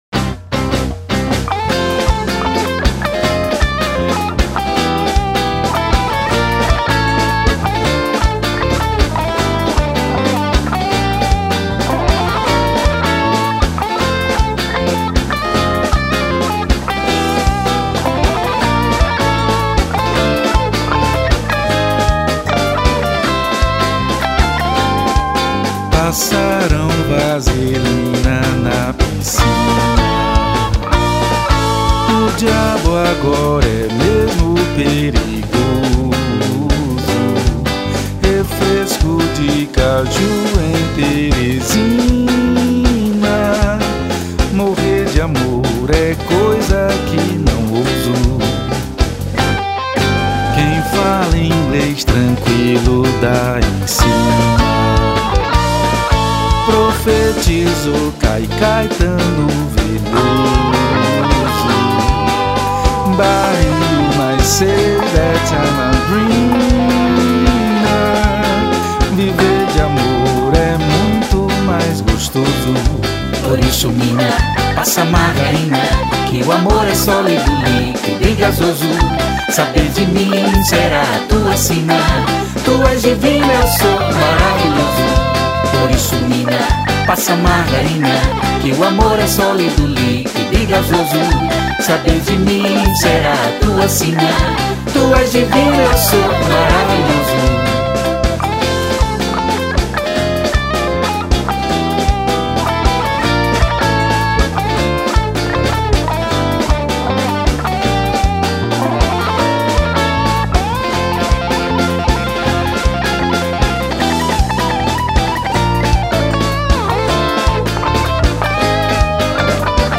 593   04:09:00   Faixa:     Frevo